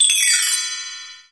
727 Starchime.wav